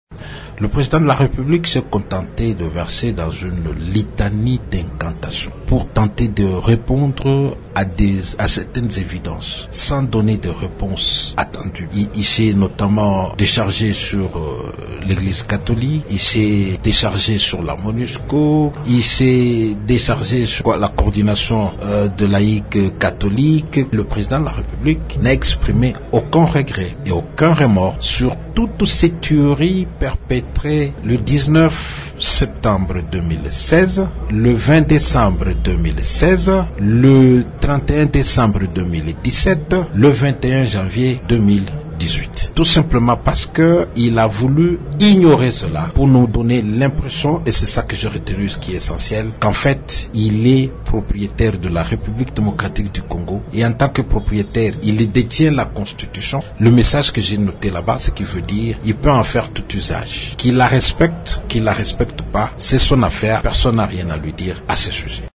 Vous pouvez suivre la réaction de Claudel Lubaya dans cet extrait sonore :